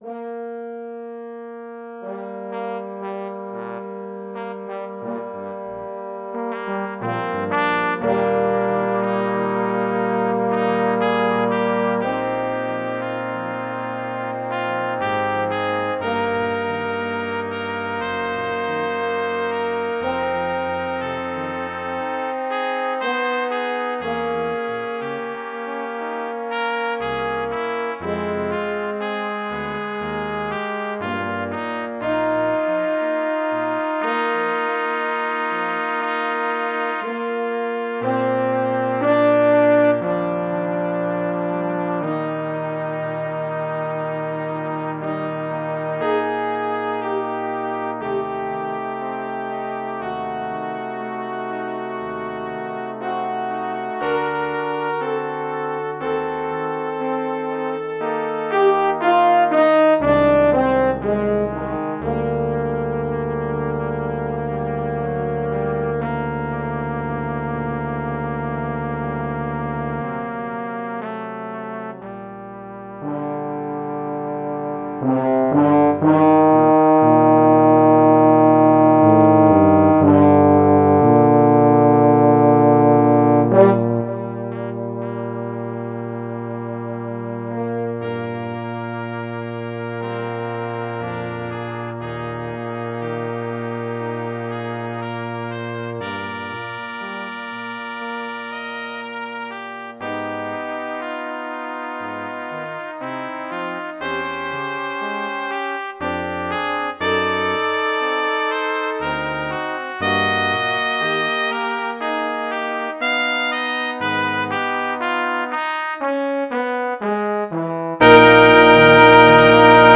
Music for Brass